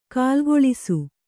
♪ kālgoḷisu